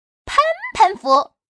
Index of /poker_paodekuai/update/1527/res/sfx/changsha_woman/